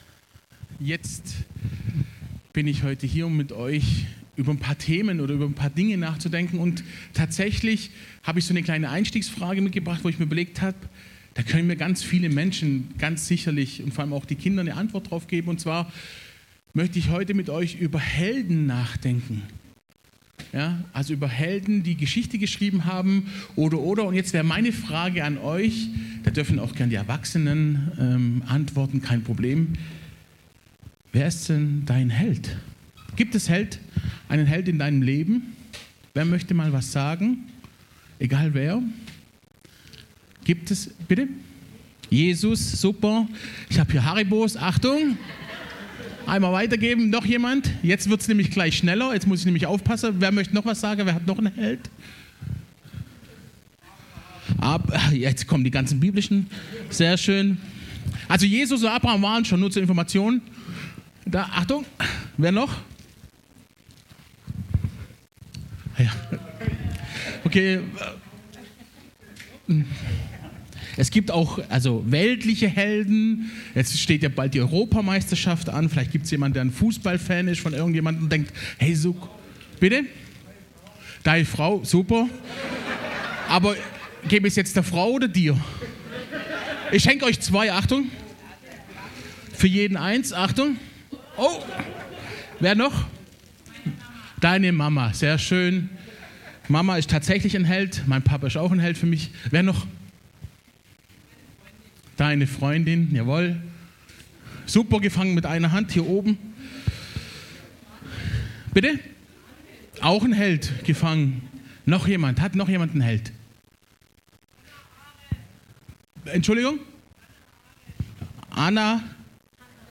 Predigt vom 09. Juni 2024 – Vormittag – Süddeutsche Gemeinschaft Künzelsau